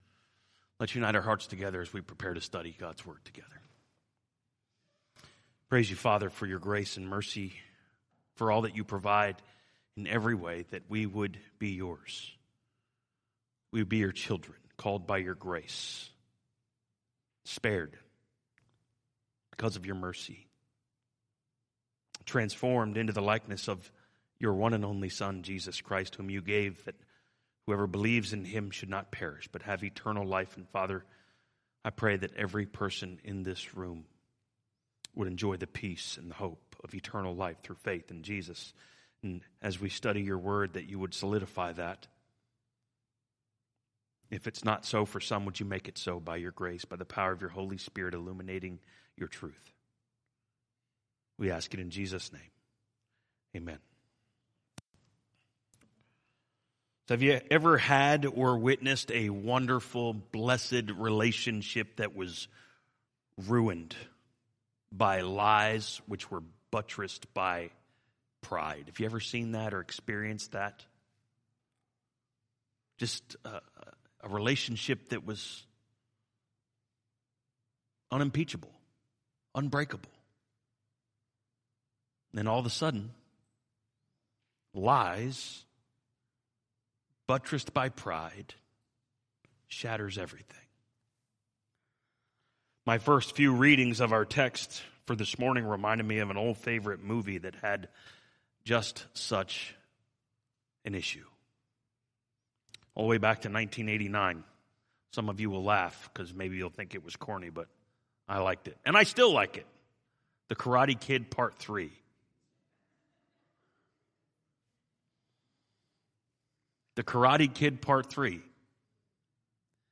Message: